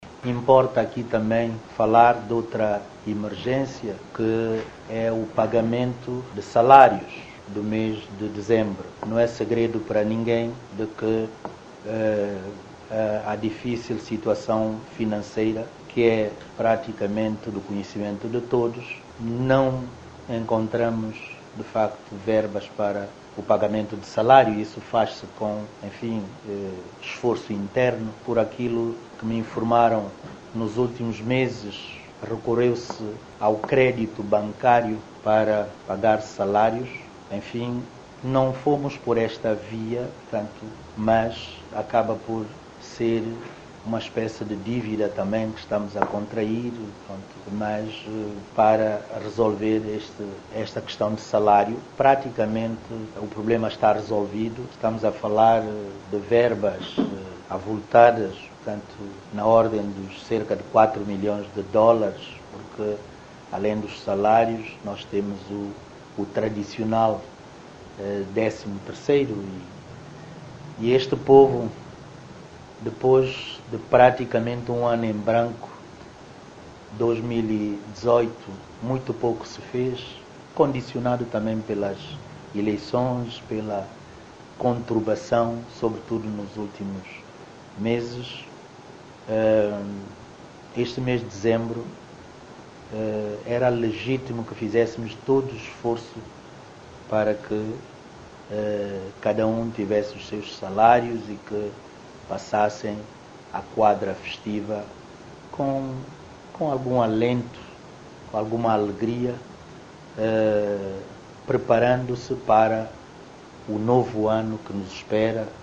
O chefe do governo são-tomense fez estas declarações hoje num autêntico balanço de pouco mais de uma semana da sua governação diante dos jornalistas, tendo sublinhado que “além do salário [de Dezembro] nós teremos também o tradicional 13º”.
Ouça Primeiro-Ministro Bom Jesus sobre 13º e salário de Dezembro